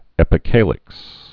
(ĕpĭ-kālĭks, -kălĭks)